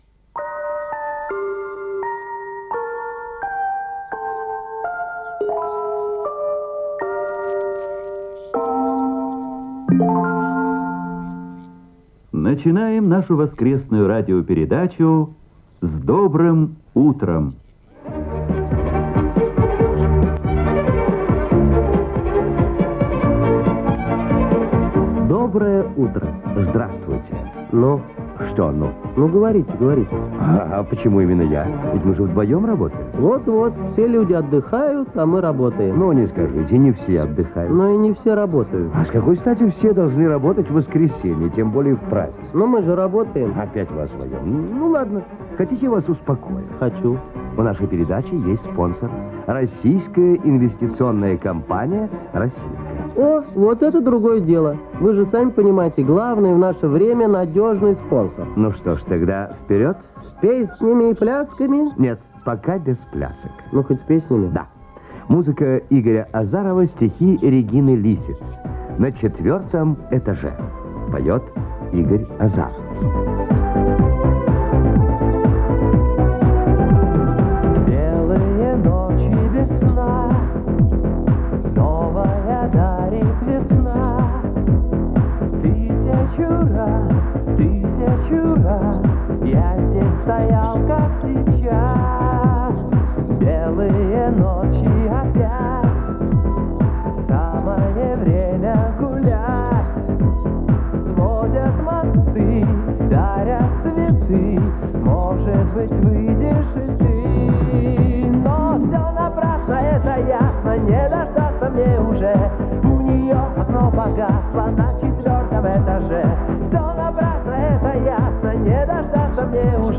Спонсоры, референдум, ваучеры, инфляция, дефицит продуктов. Оцифровка бытовой катушки.